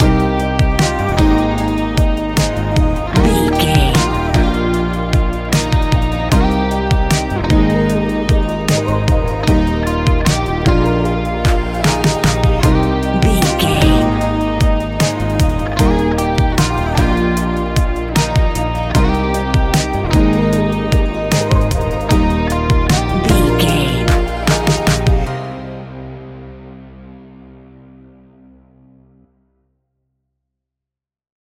Ionian/Major
D♯
ambient
electronic
new age
chill out
downtempo
synth
pads